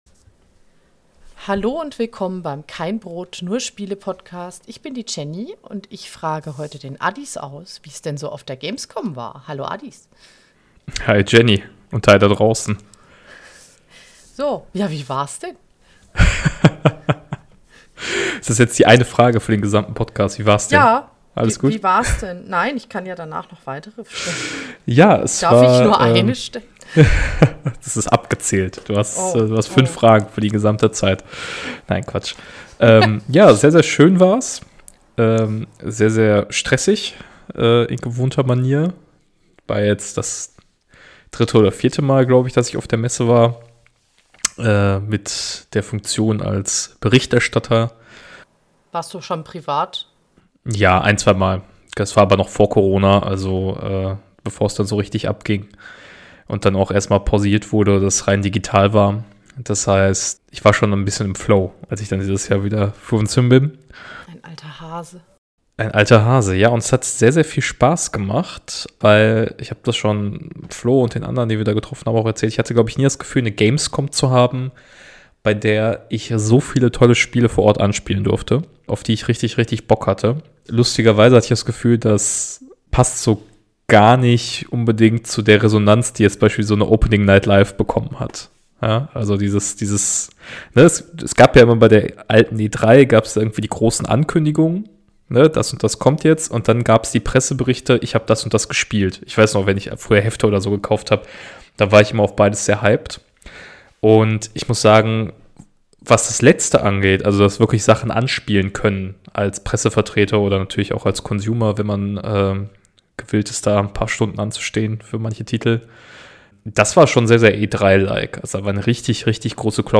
Und das Kind wollte nicht einschlafen - DAS hört ihr.